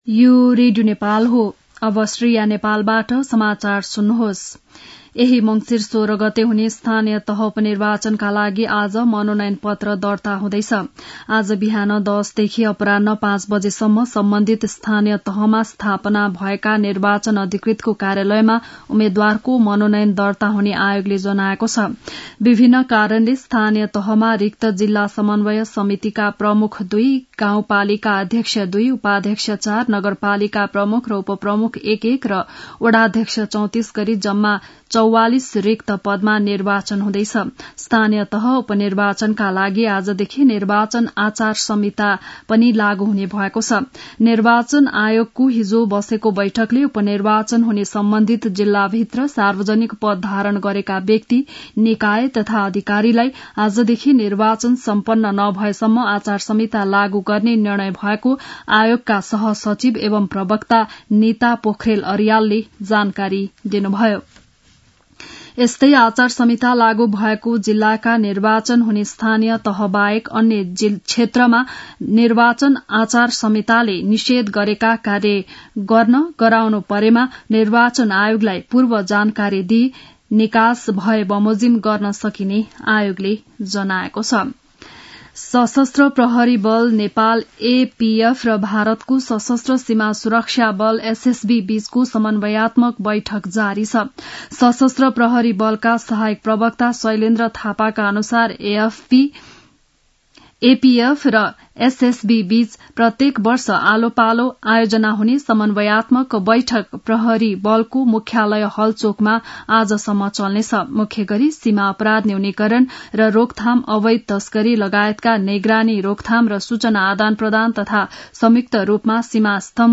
बिहान ११ बजेको नेपाली समाचार : ३ मंसिर , २०८१
11-am-news-1-3.mp3